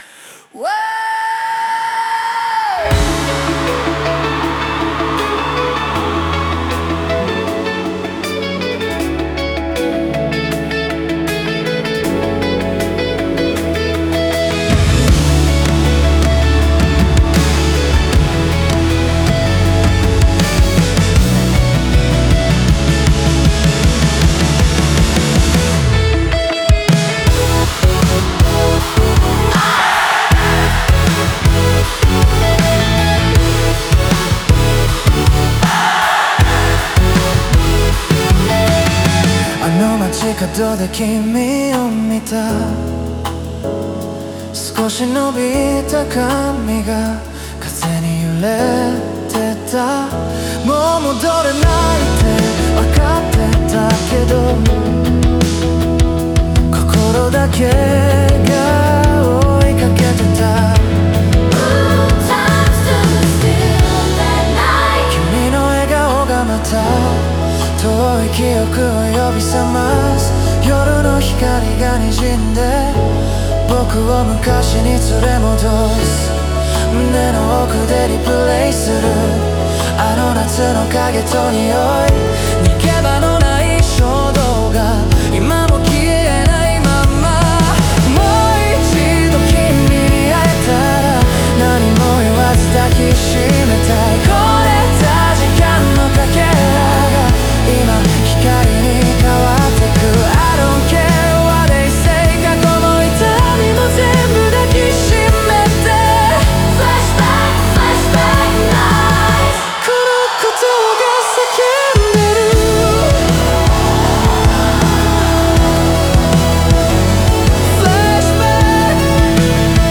オリジナル曲♪
この歌詞は、かつての恋人と偶然再会した男性の心情を、エレクトロで疾走感あるリズムに乗せて描いた物語である。
ライブ演出や観客の熱気がその感情を増幅し、懐かしさと再生の瞬間を劇的に演出している。